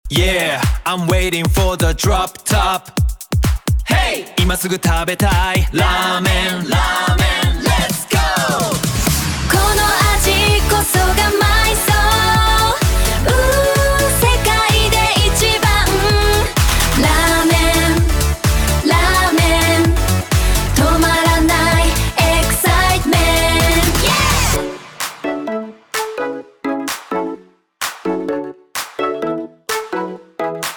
テキストで指示を出すだけで、歌詞の書き下ろしからメロディ、歌声の生成までをわずか数十秒で行なってくれます。
スタイル K-POP
K-POP 特有のハイテンションなダンスナンバーに仕上がっていて、クオリティに驚きました
ただ、日本語としての発音に違和感があるところや、何と言っているのか聞き取れないところもありました。